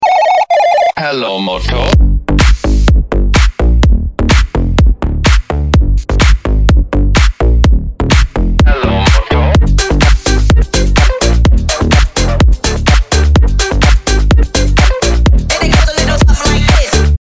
Phone Ringtones